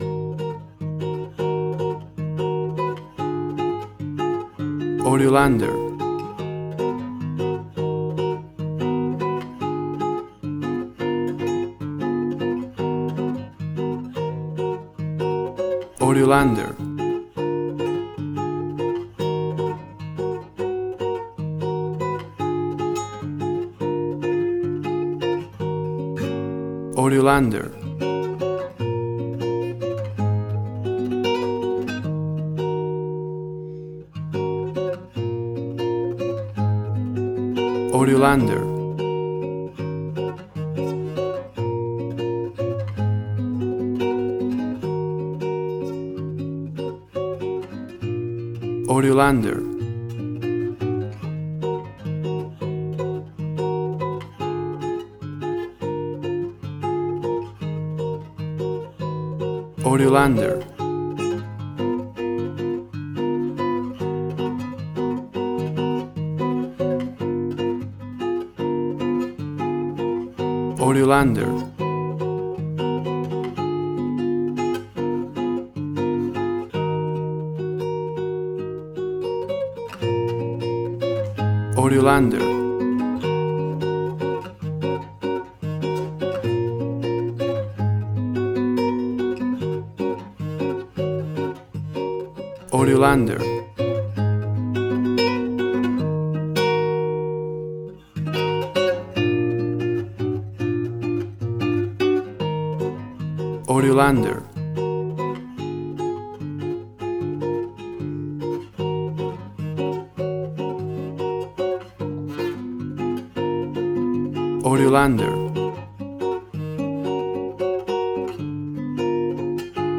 An exotic and colorful piece of Brazilian and Latin music.
Tempo (BPM): 75